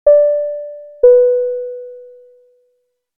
Seatbelt Sound Effect: Ding Tone for Aircraft Cabin Chime
Ding tone. The chime actively signals passengers in an aircraft to fasten their seatbelts. Aircraft cabin chime.
Seatbelt-sound-effect.mp3